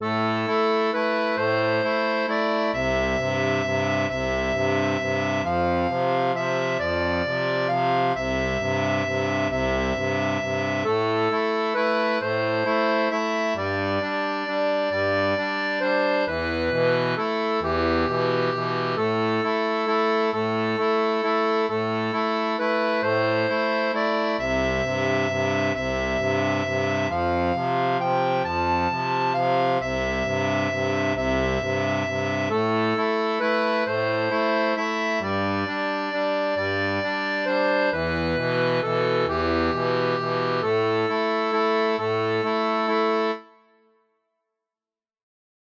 Chant de marins